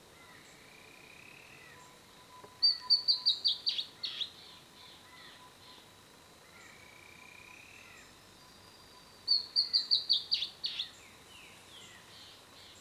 Bertoni´s Antbird (Drymophila rubricollis)
Life Stage: Adult
Province / Department: Misiones
Location or protected area: Bio Reserva Karadya
Condition: Wild
Certainty: Recorded vocal
tiluchi-colorado.mp3